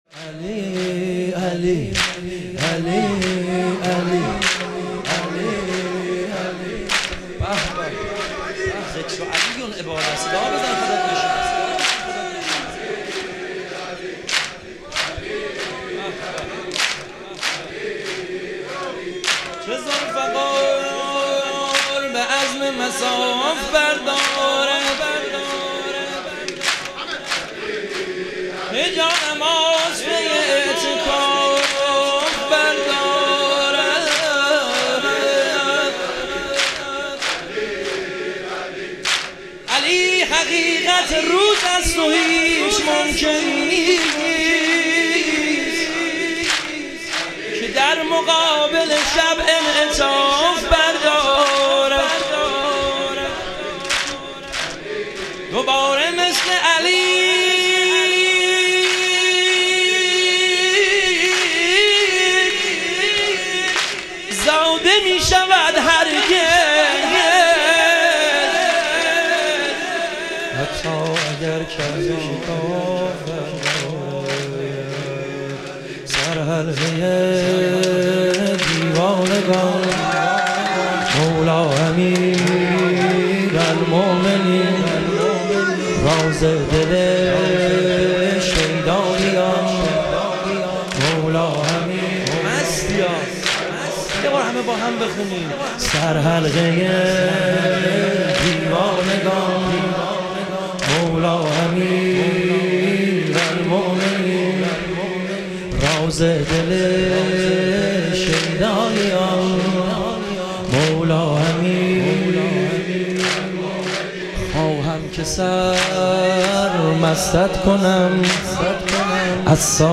شهادت امام کاظم علیه السلام - واحد